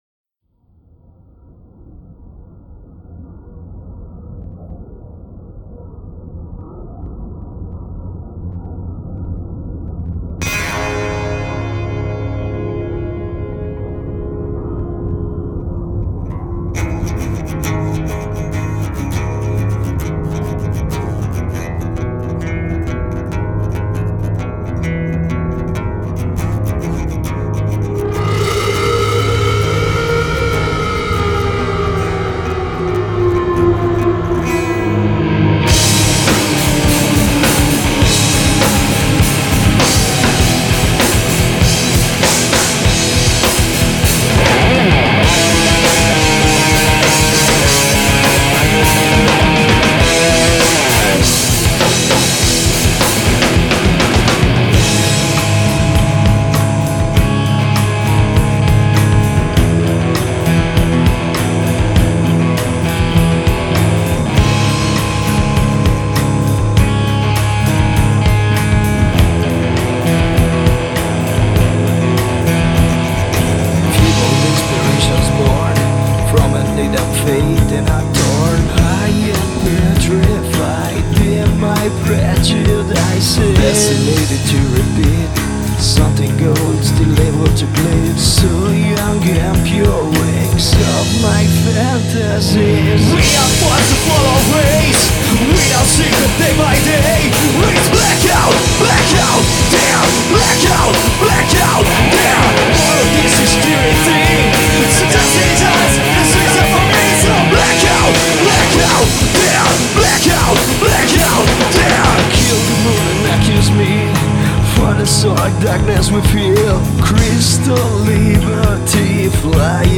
bassista
chitarrista
facendo casino e suonando un po’ di vecchio punk.
alla voce e chitarra solista
alla batteria